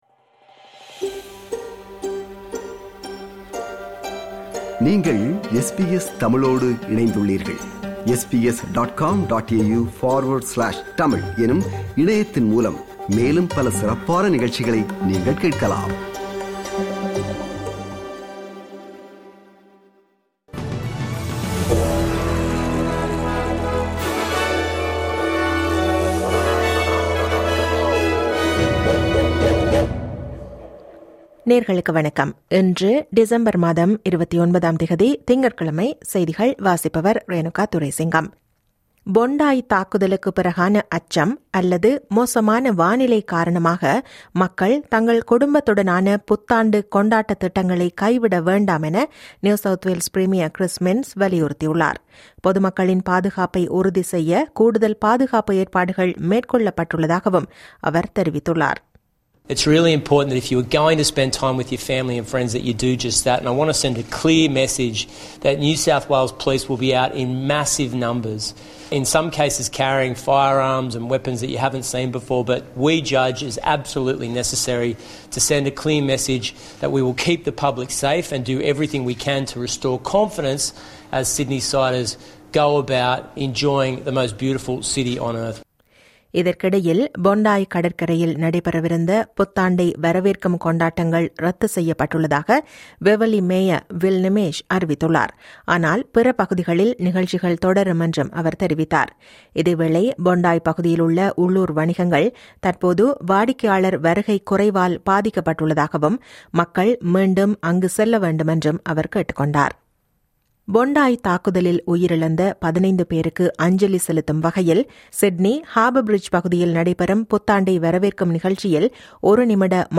இன்றைய செய்திகள்: 29 டிசம்பர் 2025 - திங்கட்கிழமை
SBS தமிழ் ஒலிபரப்பின் இன்றைய (திங்கட்கிழமை 29/12/2025) செய்திகள்.